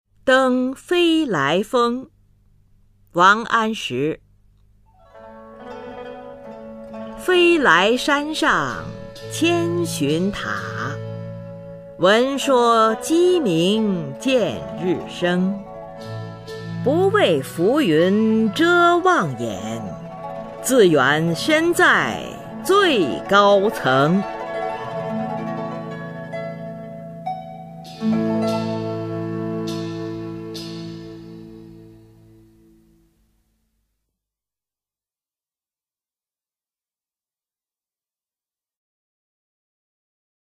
[宋代诗词诵读]王安石-登飞来峰 宋词朗诵